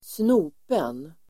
Ladda ner uttalet
Uttal: [²sn'o:pen]